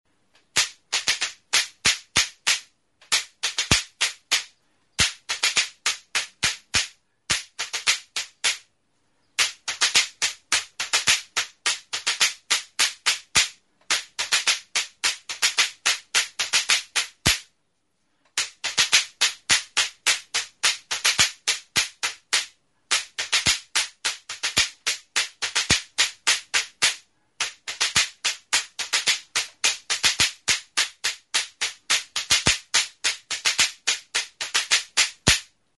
Idiophones -> Struck -> Indirectly
Recorded with this music instrument.
Arto zurtar zati batekin egina da.